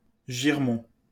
Girmont (French pronunciation: [ʒiʁmɔ̃]